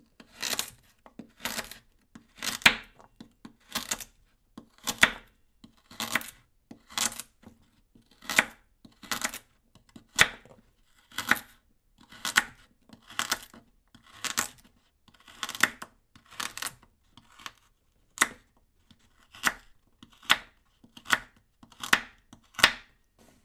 9. Ножом режут овощ